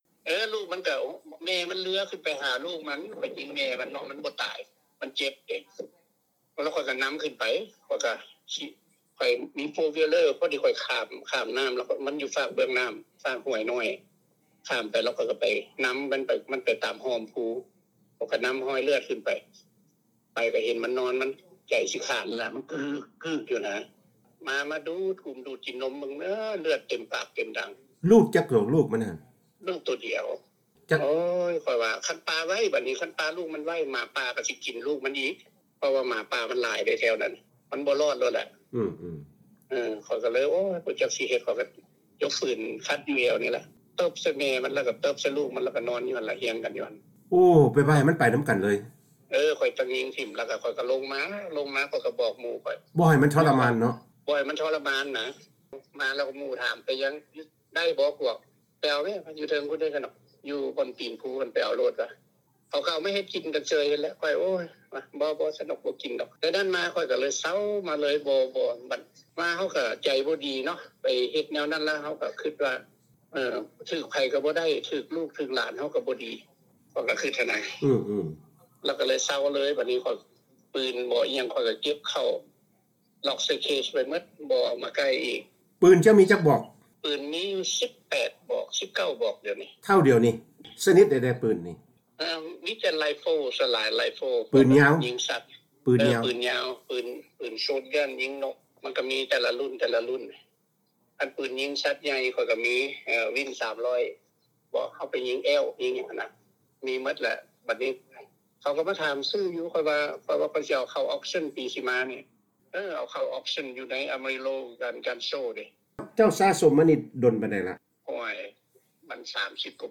ວີໂອເອລາວ ໂອ້ລົມກັບບັນດານັກລ່າເນື້ອ ລາວອາເມຣິກັນ ກ່ຽວກັບປະສົບການ ຂອງພວກເຂົາເຈົ້າ